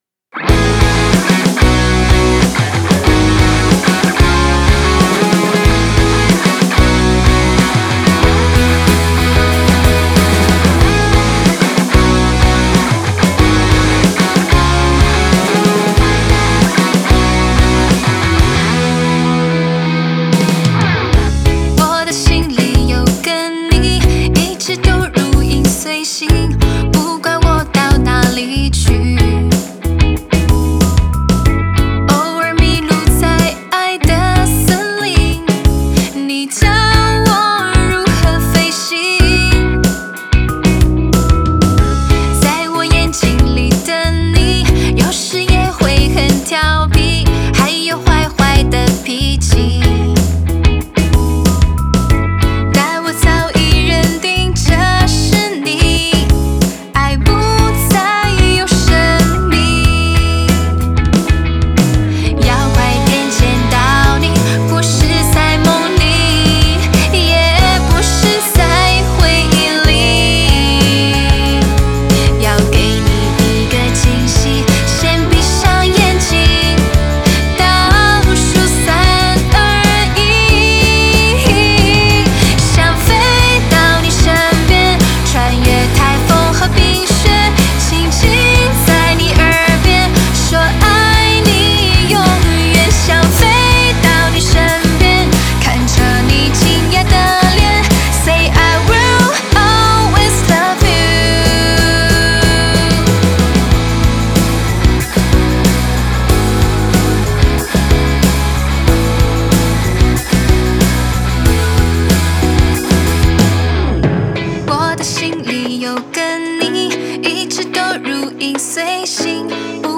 微电微甜情歌曲+情感唱腔！